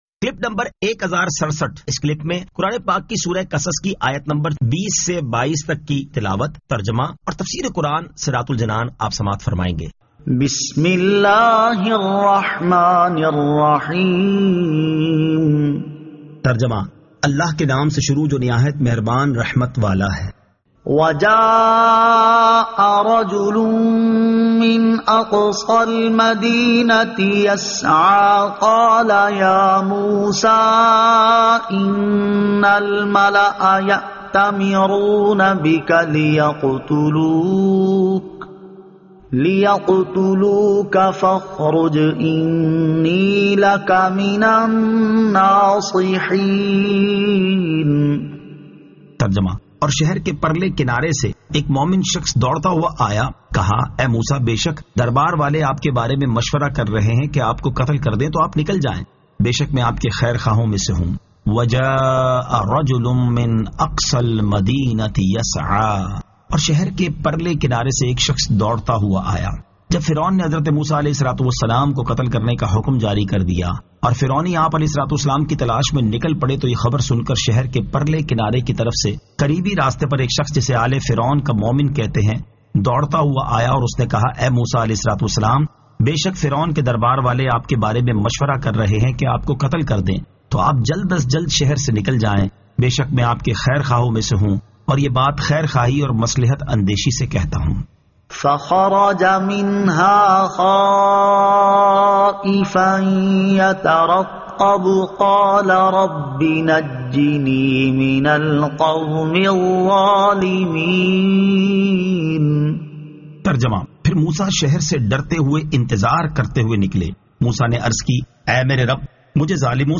Surah Al-Qasas 20 To 22 Tilawat , Tarjama , Tafseer